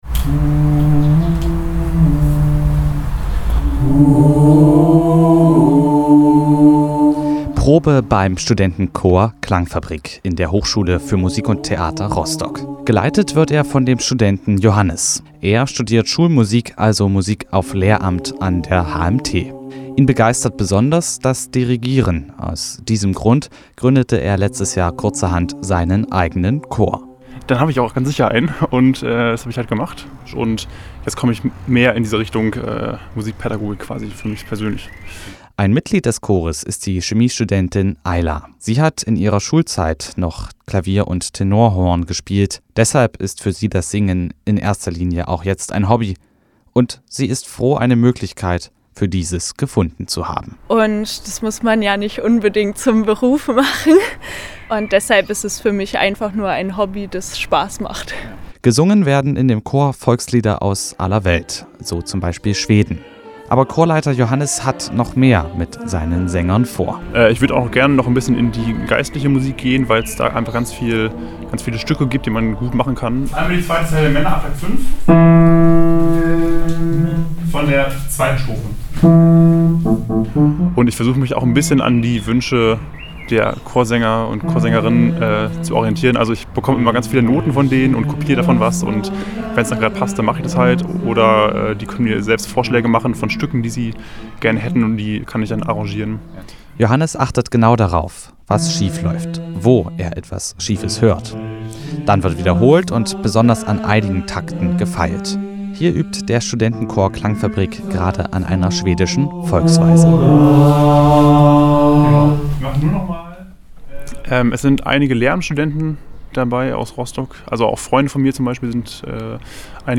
Der neue Studierendenchor „Klangfabrik“ aus Rostock singt viel vom Volkslied über Klassiker der 3-stimmigen Chormusik bis hin zu groovigen Poparrangements.